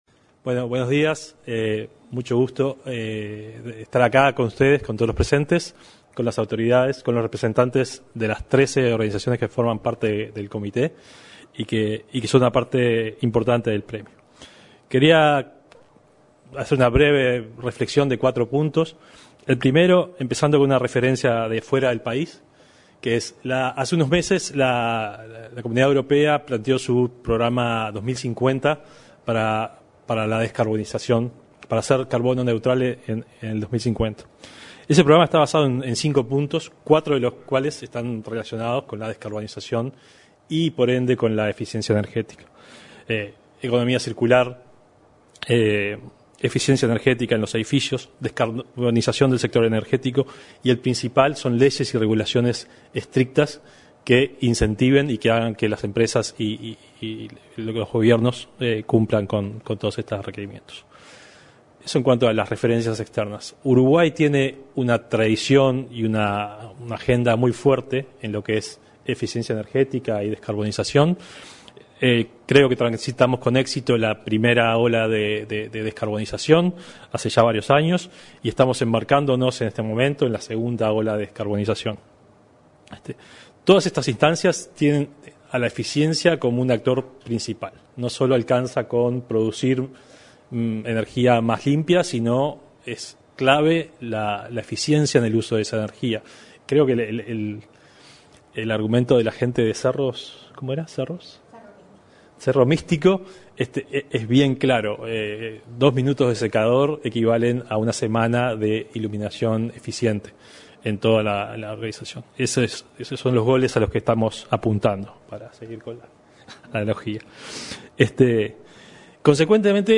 En el lanzamiento del Premio Nacional de Eficiencia Energética 2022, se expresó el presidente de la Agencia Nacional de Investigación e Innovación,